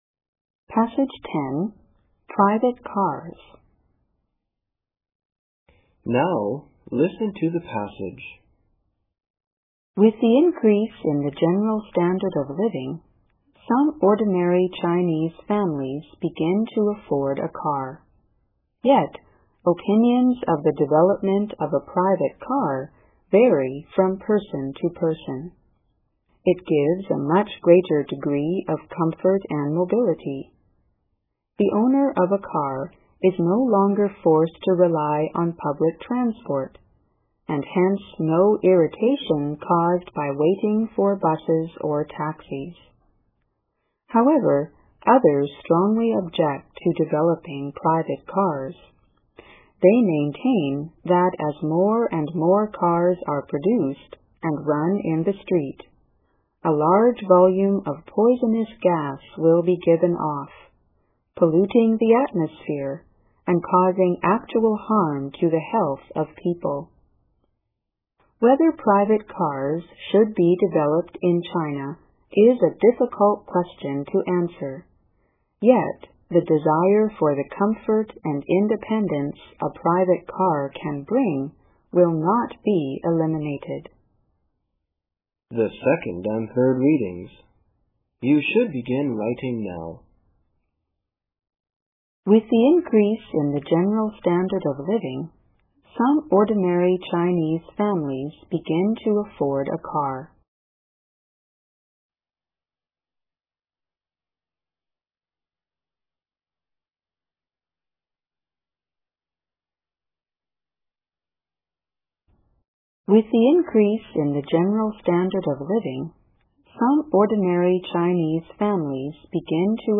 英语专业四级听写50篇(10):Private Cars